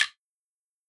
Guiro Groovin.wav